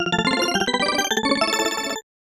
Jingle_Achievement_01
8-bit 8bit Achievement Game Jingle Nostalgic Old-School SFX sound effect free sound royalty free Gaming